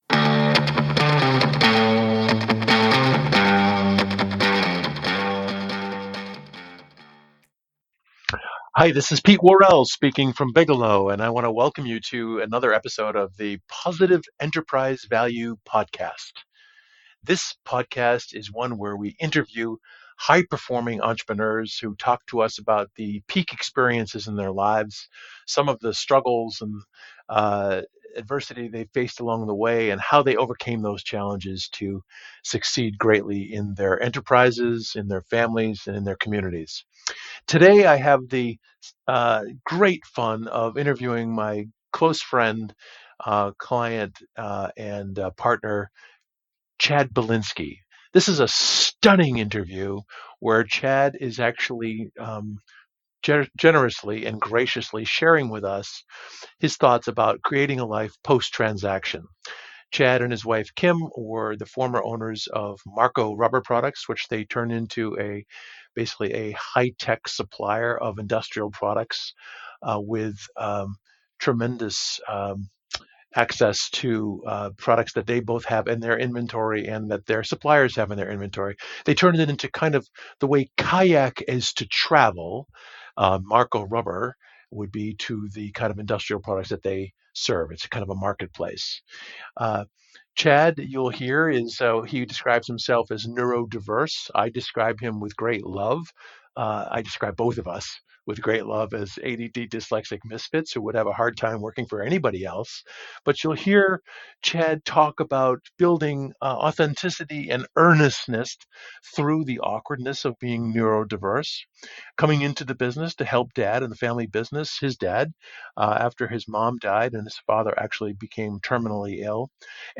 In this podcast I interview high-performing Entrepreneur Owner-Managers who share with us the peak experiences in their lives, some of the struggles and adversity they've faced along the way, and how they overcame those challenges to succeed greatly in their enterprises, families, and communities.